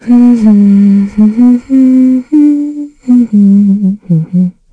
Isolet-Vox_Hum.wav